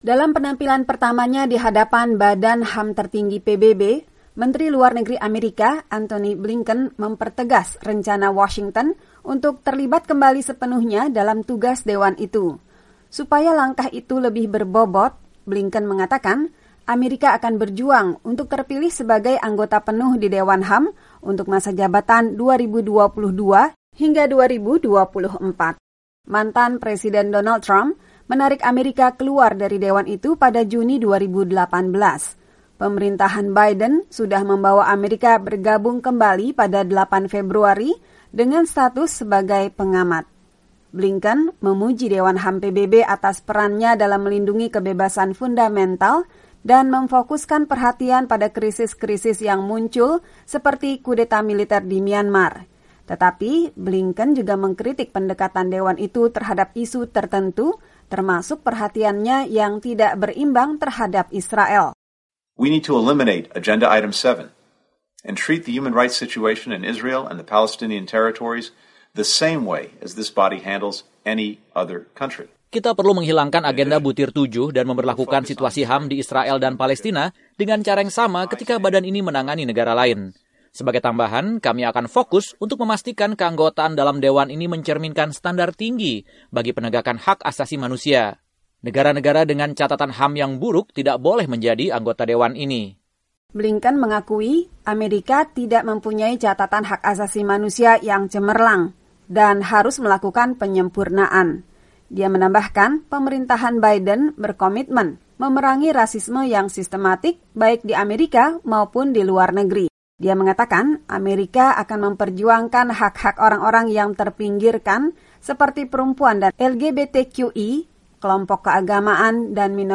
Menlu Amerika Antony Blinken memberikan pidato virtual di depan Dewan HAM PBB di Jenewa, Rabu (24/2).